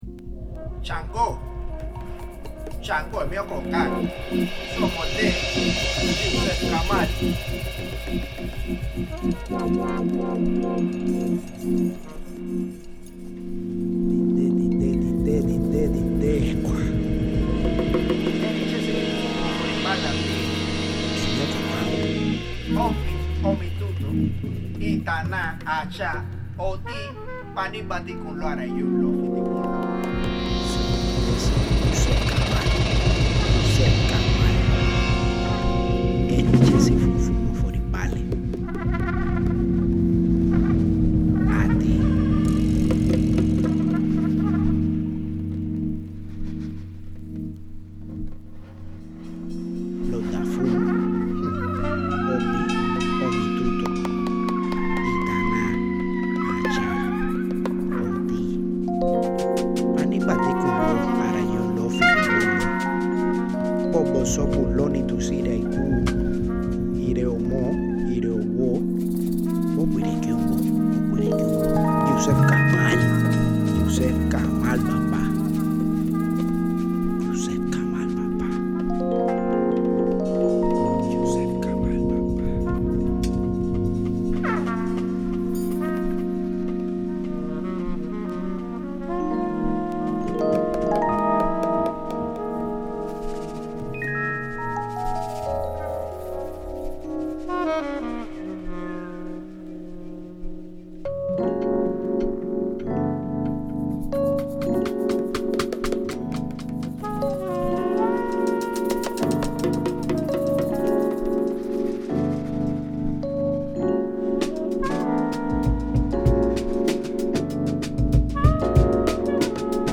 スピリチュアル〜メロウをも呑み込む新世代のジャズを繰り広げる。